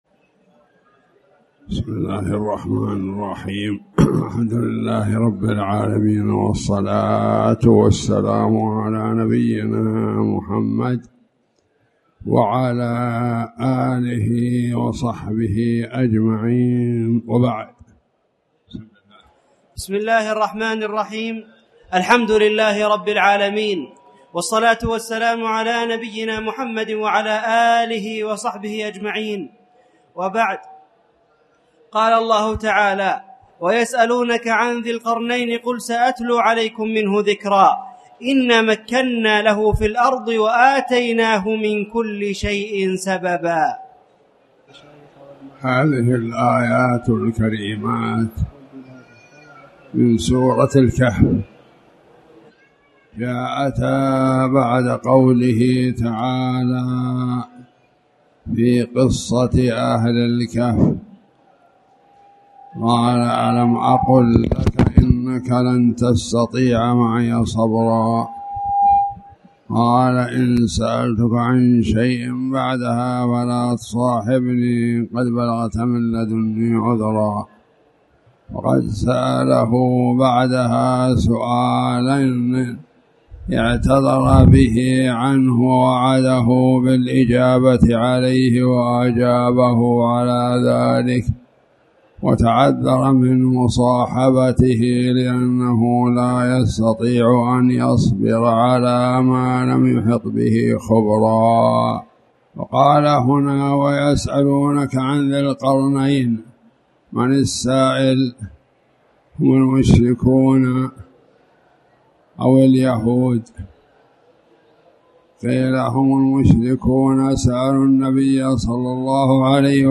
تاريخ النشر ٤ ربيع الأول ١٤٣٩ هـ المكان: المسجد الحرام الشيخ